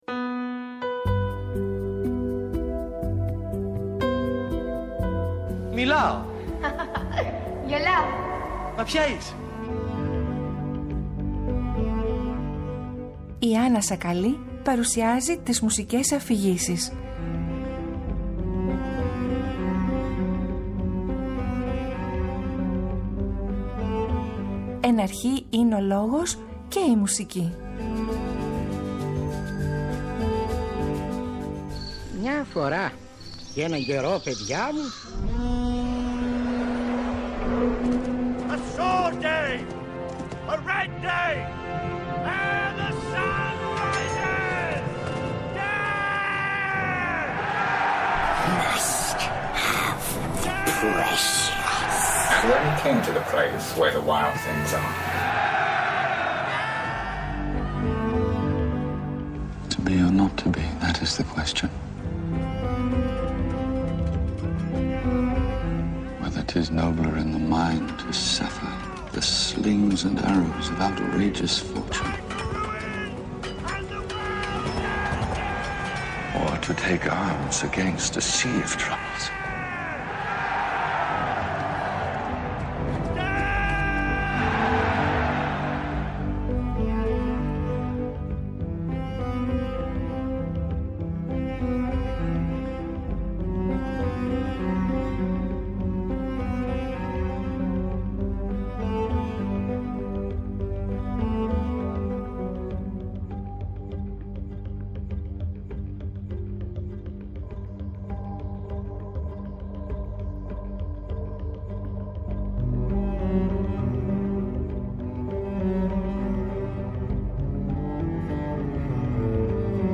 Η συζήτηση πλαισιώνεται μουσικά με χαρακτηριστικά αποσπάσματα από την Όπερα.